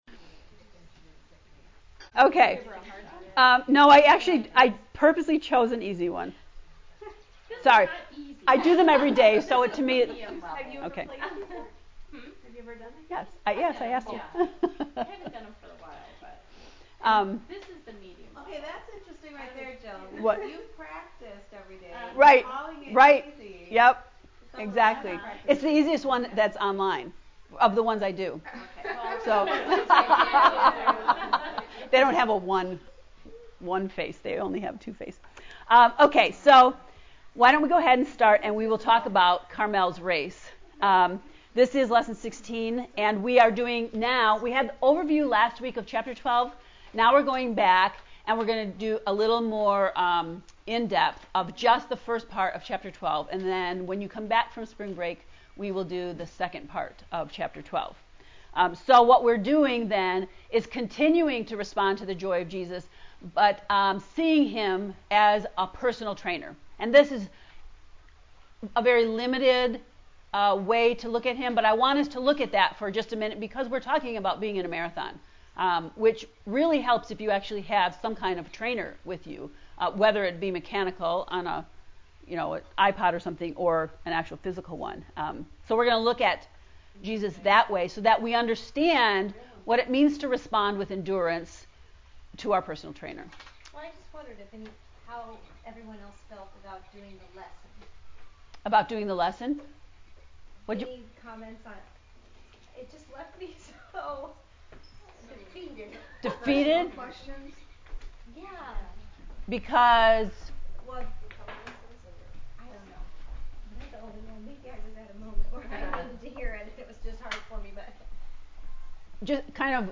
heb-ii-lecture-16.mp3